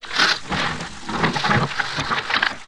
ALIEN_Communication_06_mono.wav